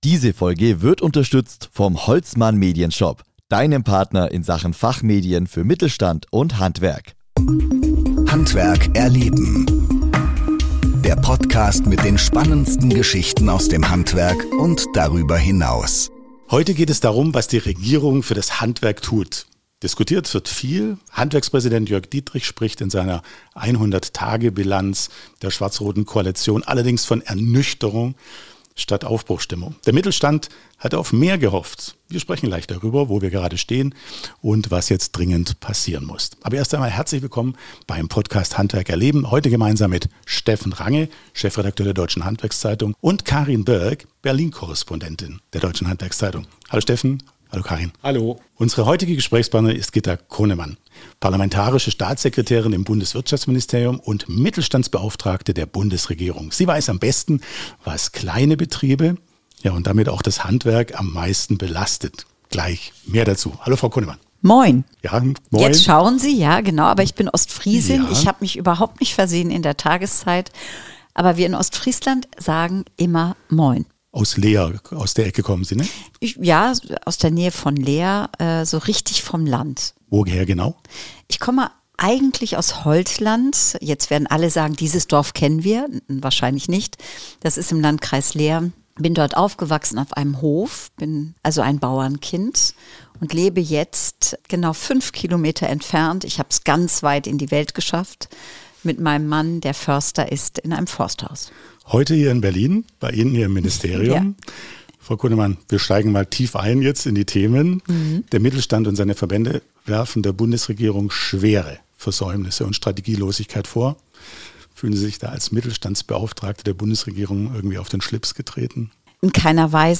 Im Gespräch geht es um die großen Herausforderungen für das Handwerk: von hohen Energiekosten über den Fachkräftemangel bis zum Bürokratieabbau. Außerdem berichtet Gitta Connemann, welche Entlastungen bereits beschlossen sind, wo noch nachgebessert werden muss und wie die Politik Betriebe stärken will.